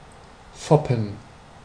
Ääntäminen
IPA : /pʊt ɒn/